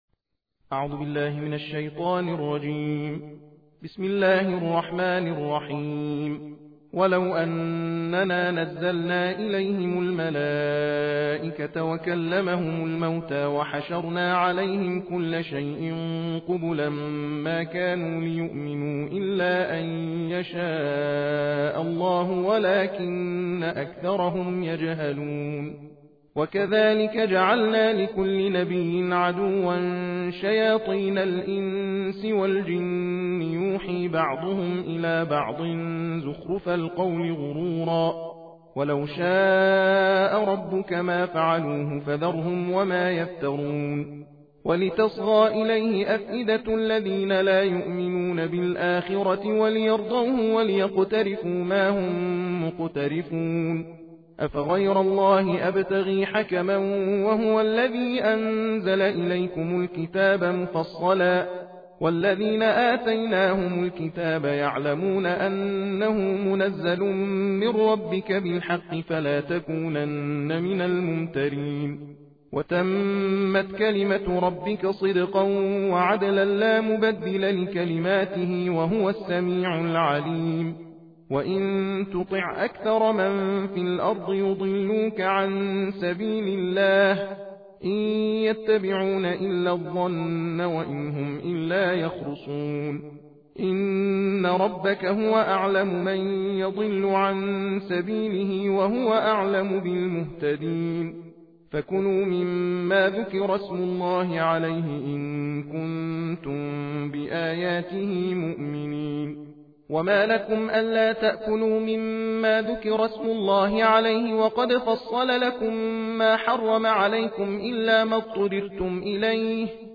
تحدیر جزء هشتم قرآن کریم